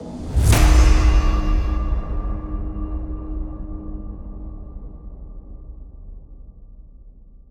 impact2.wav